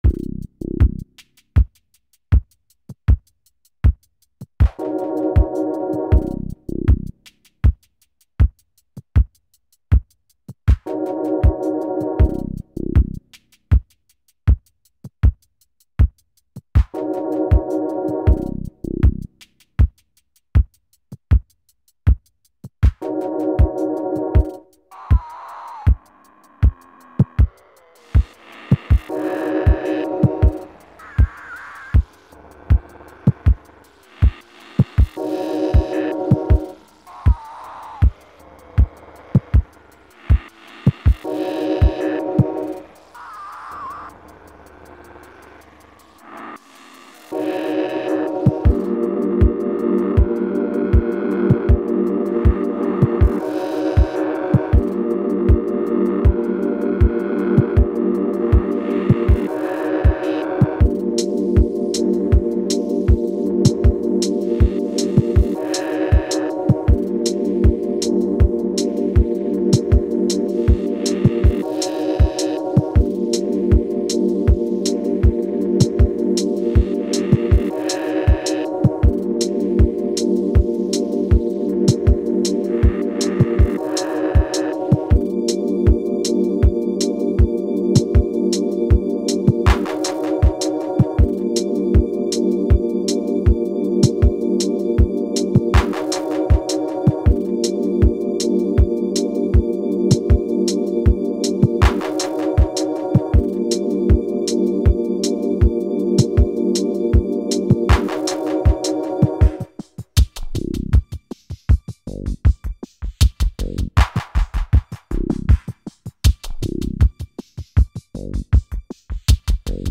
Glitchy, dancey, melodic.